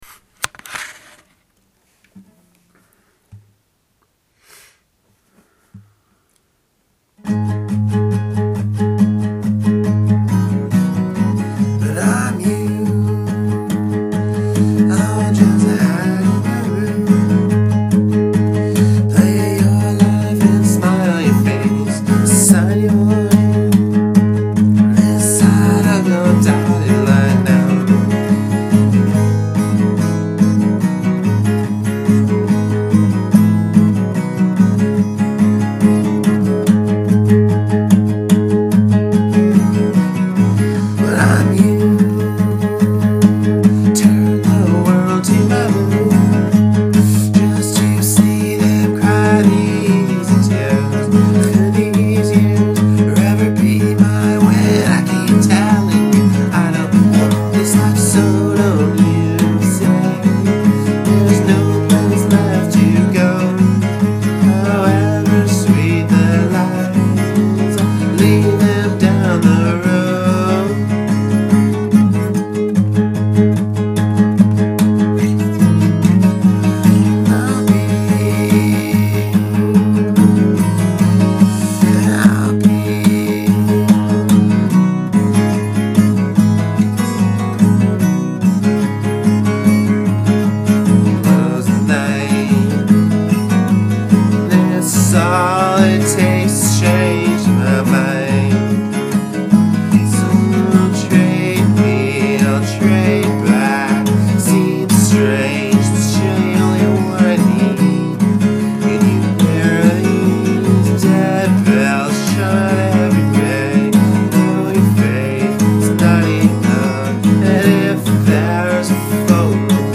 Very, very raw.